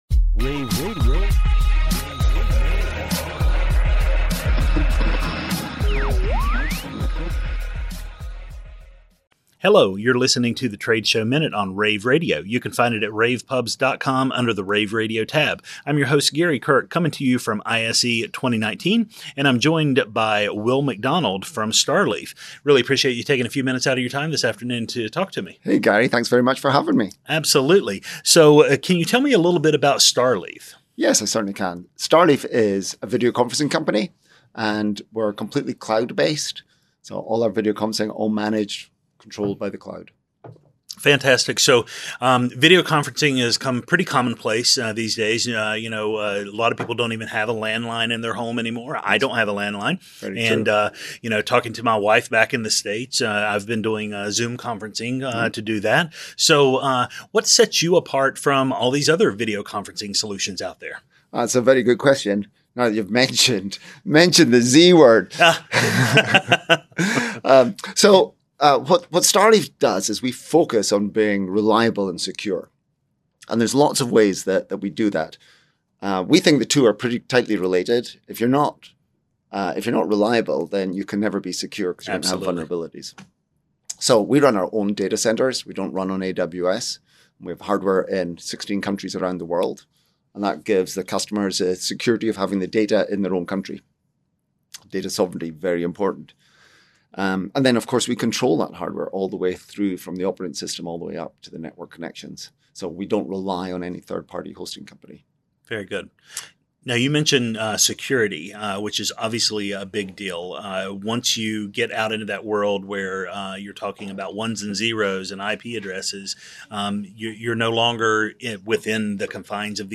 February 6, 2019 - ISE, ISE Radio, Radio, rAVe [PUBS], The Trade Show Minute,
interviews